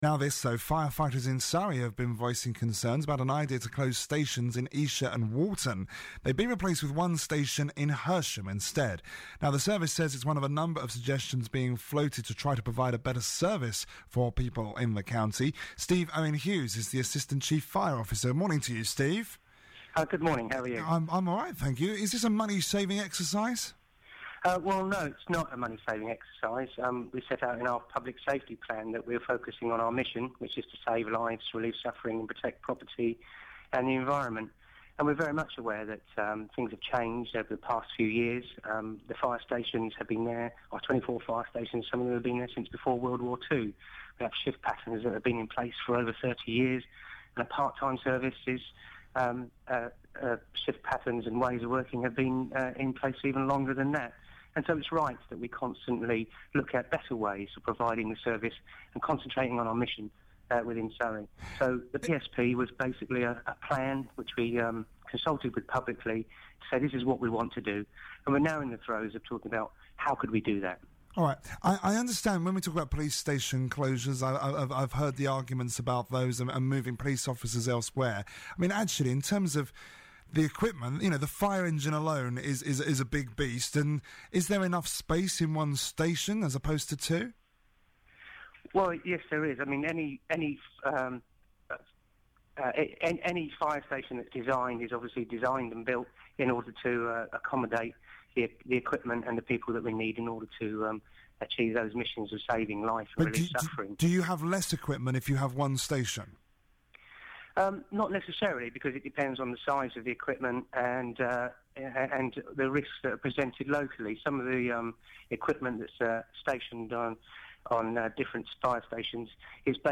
Assistant Chief Fire Officer interviewed on BBC Surrey